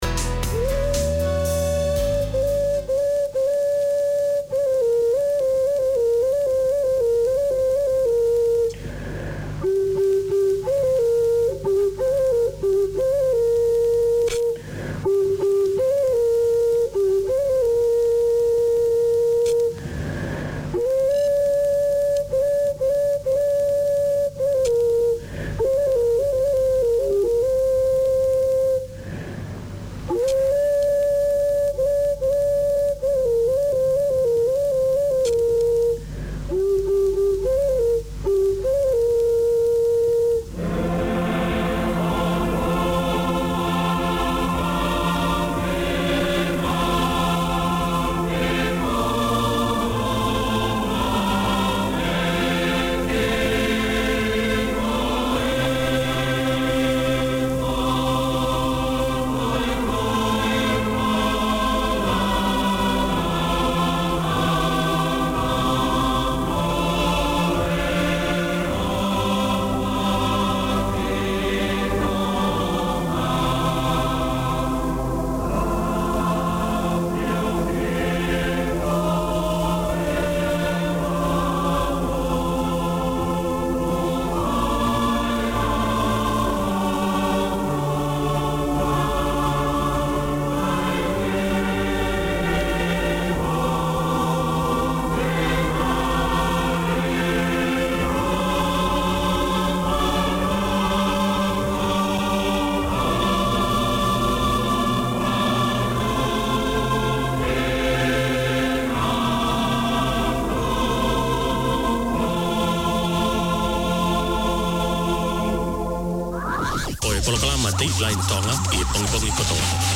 A new era in Tongan broadcasting, this is a talk show that focuses on community successes and debating issues from every angle relevant to Tongan wellbeing. Four mornings a week, the two hour programmes canvas current affairs of concern to Tongans and air in-depth interviews with Tongan figureheads, academics and successful Tongans from all walks of life.